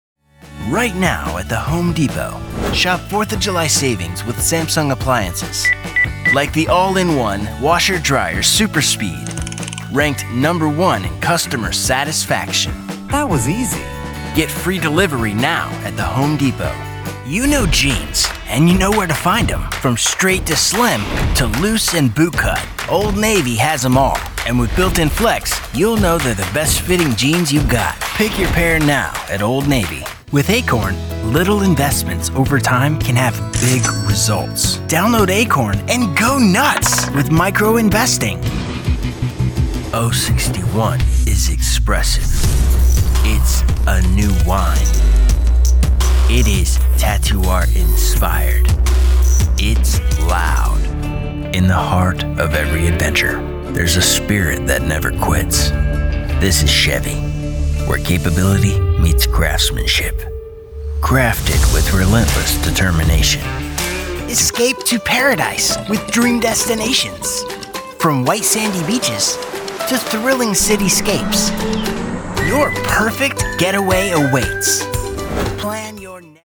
voz de barítono dinámica y áspera con un estilo auténtico y conversacional, ideal para anuncios, narraciones y personajes.
Demo comercial
Tengo un estudio de grabación profesional en casa, así como sólidas habilidades de mezcla y masterización.